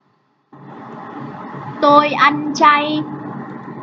實用越語教學
越語發音教學由非凡教育中心提供